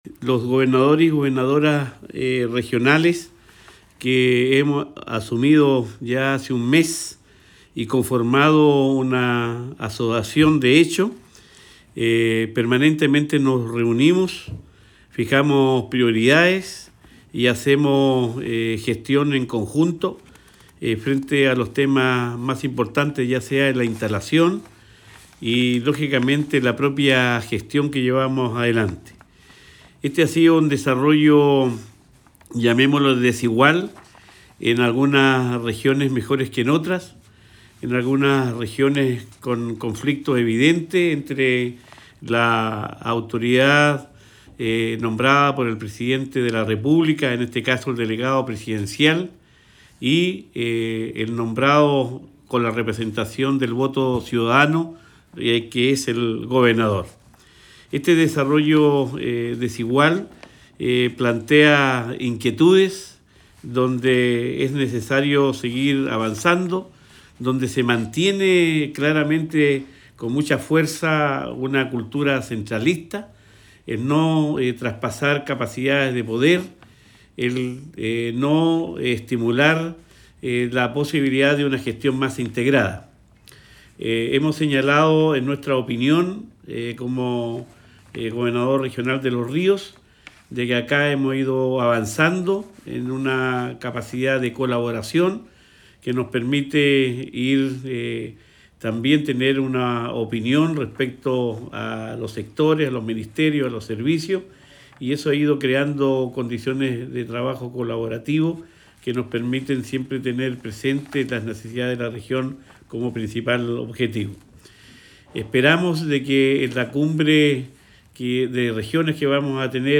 – Luis Cuvertino – Gobernador de la Región de Los Ríos
Cuña_Luis-Cuvertino_Mes-de-gestión.mp3